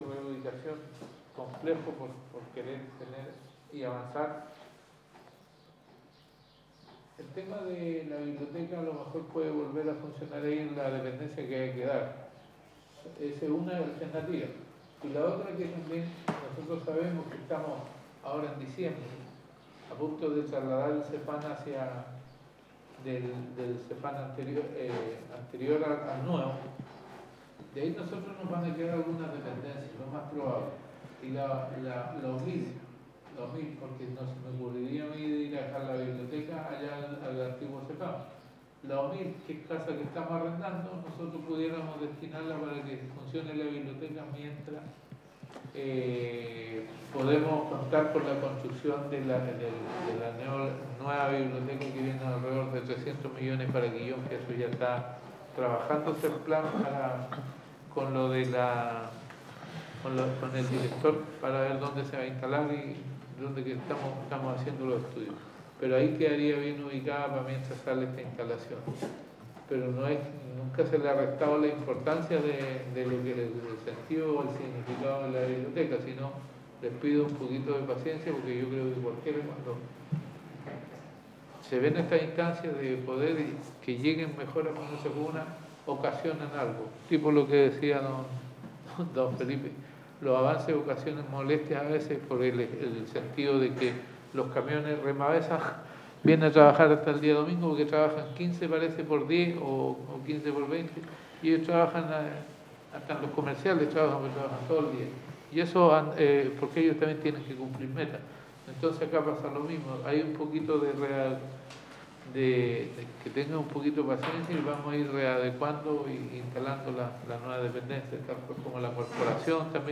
Audio Concejo 16 de Noviembre de 2021